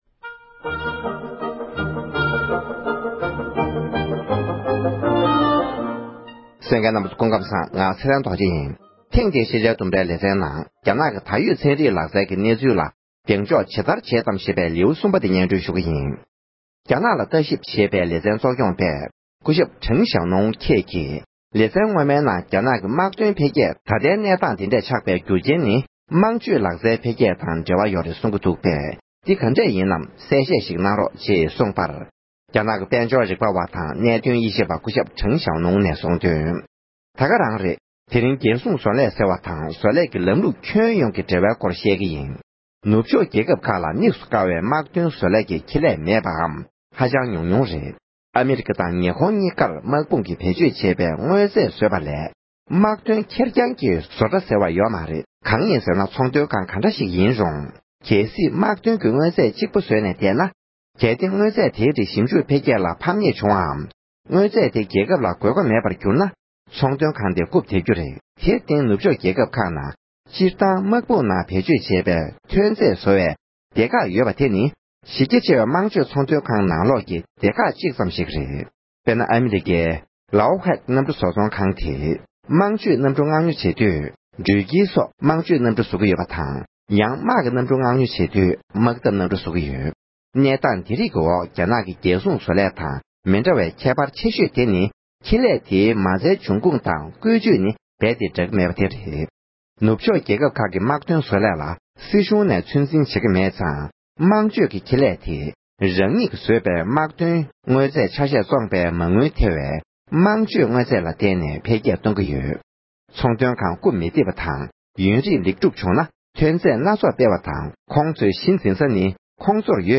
ཕབ་བསྒྱུར་དང་སྙན་སྙན་སྒྲོན་ཞུས་པའི་ལིའུ་གསུམ་པར་གསན་རོགས་ཞུ༎